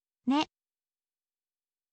ออกเสียง: ne, เนะ